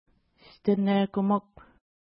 Pronunciation: stnekəmuk
Pronunciation